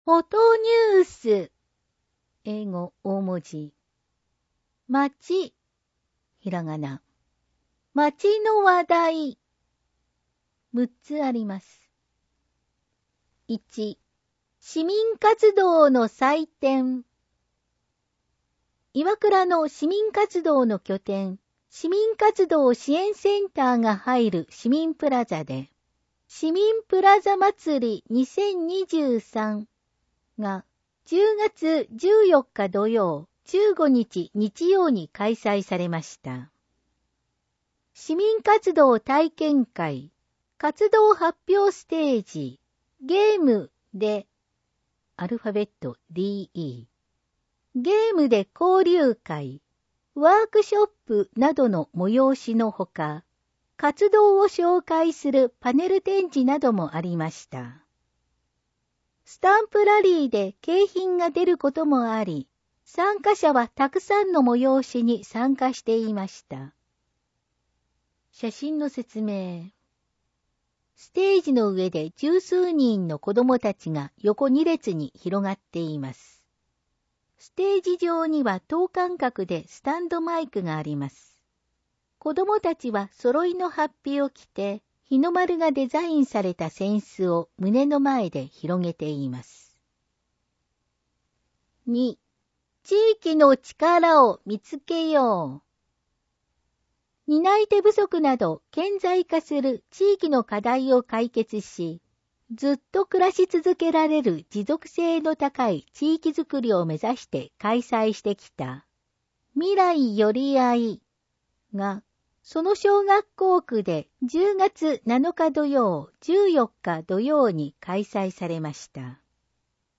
広報いわくらの音声版です。